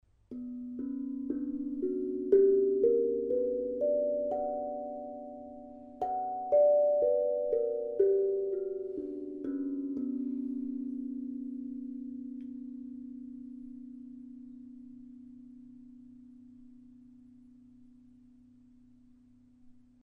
Die Zenko breitet ihre Klänge in weichen und sphärischen Wellen aus.
Solstice: B3 C#4 D4 F#4 G4 Bb4 B4 D5 F#5
Tonfolge mit Klöppeln